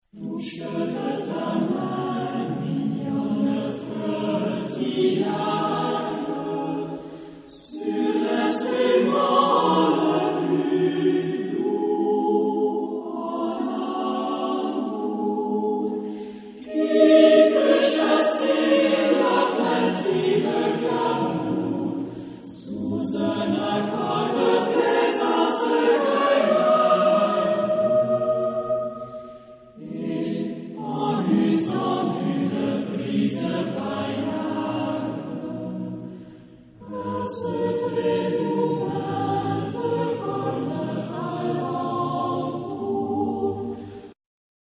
Genre-Style-Forme : Profane ; contemporain ; Chanson
Type de choeur : SATB  (4 voix mixtes )
Tonalité : mi majeur